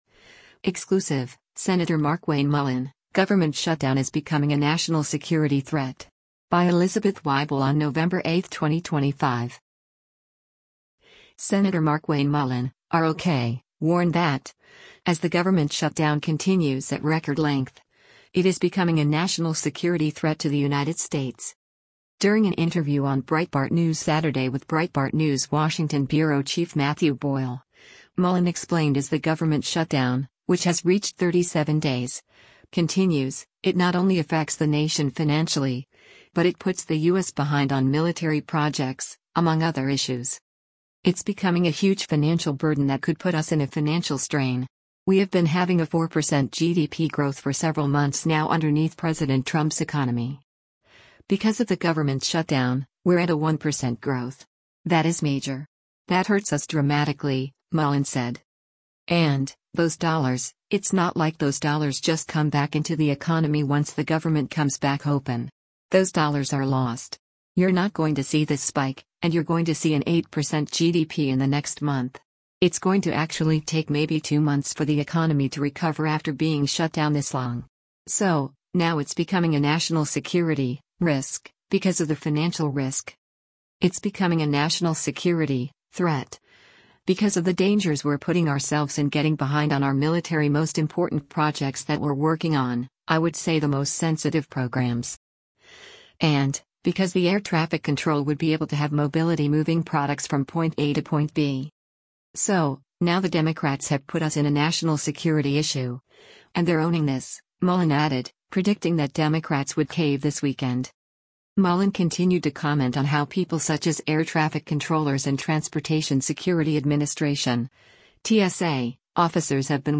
During an interview on Breitbart News Saturday